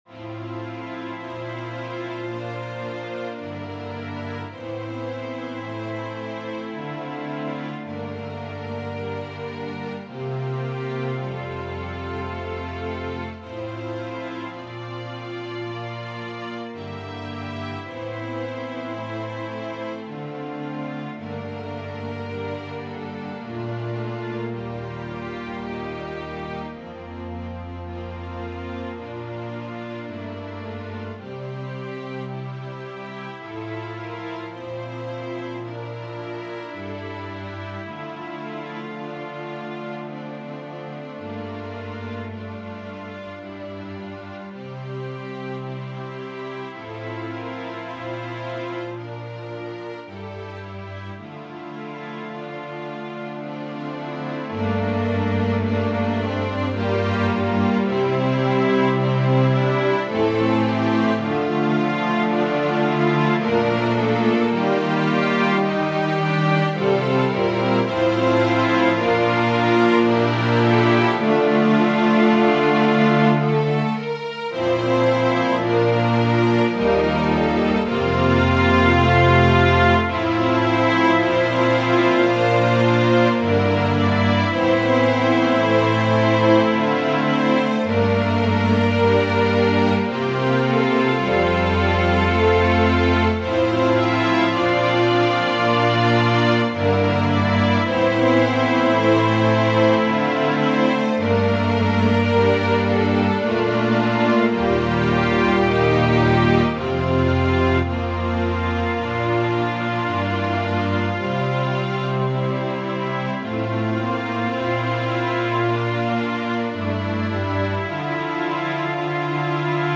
Organ/Organ Accompaniment
This is an old polish Christmas Carol that was arranged by Mack Wilberg.
This is an improvement from the last one and I merely added an extra verse a little something extra towards the end.